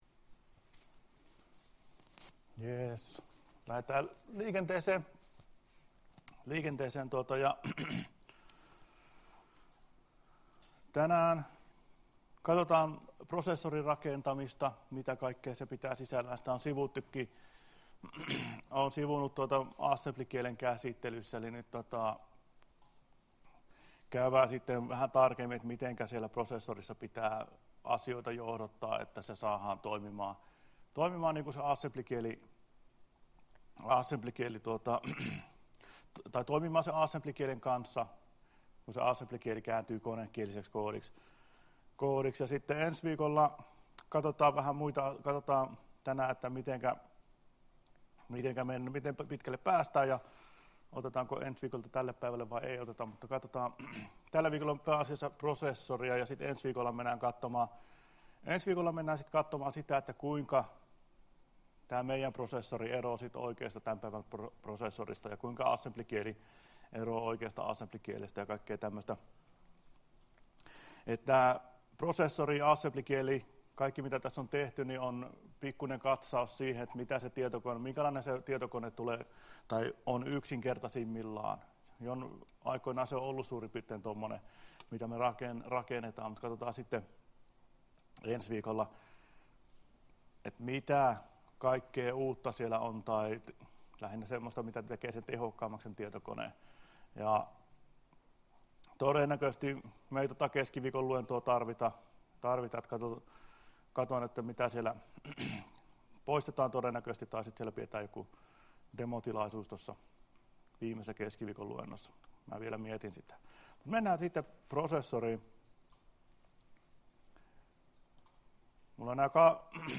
Luento 12.10.2016 — Moniviestin